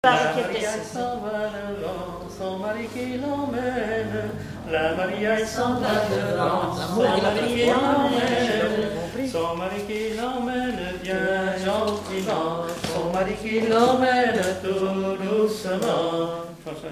gestuel : à marcher
circonstance : fiançaille, noce
Pièce musicale inédite